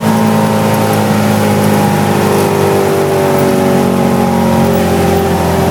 Index of /server/sound/vehicles/lwcars/uaz_452
slowdown_highspeed.wav